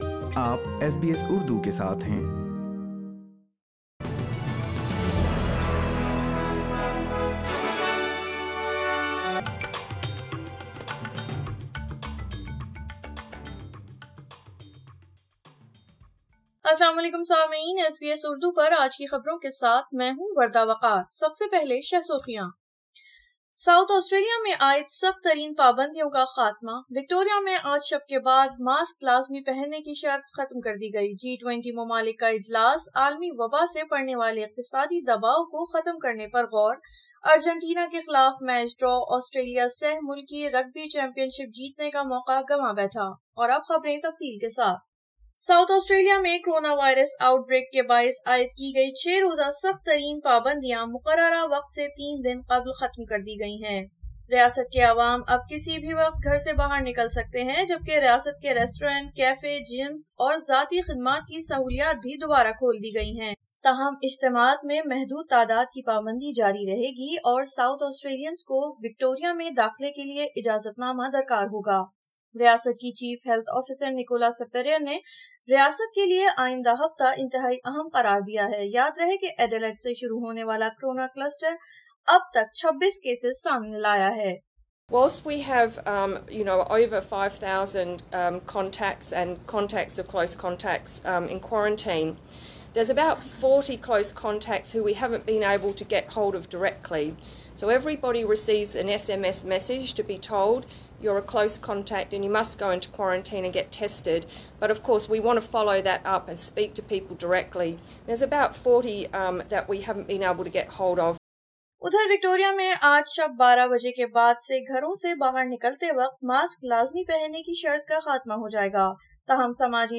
اردو خبریں 22 نومبر 2020